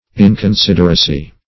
Meaning of inconsideracy. inconsideracy synonyms, pronunciation, spelling and more from Free Dictionary.
inconsideracy.mp3